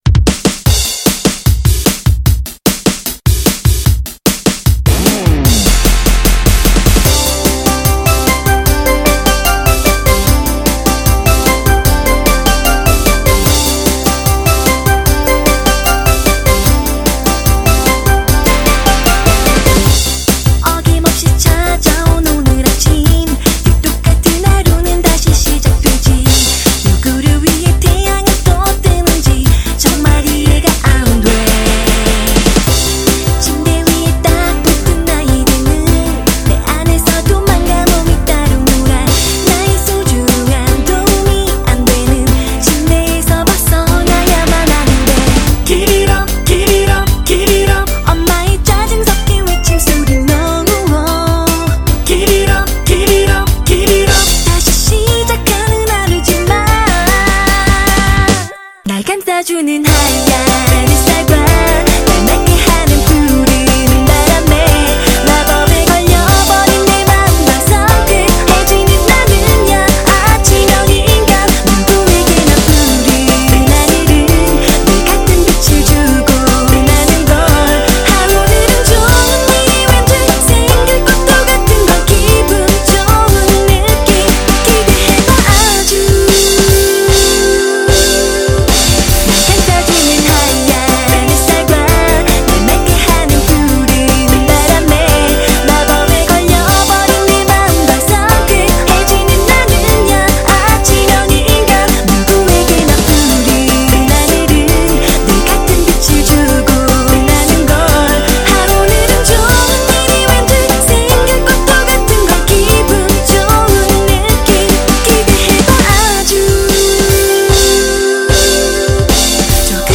BPM75-150
Audio QualityPerfect (High Quality)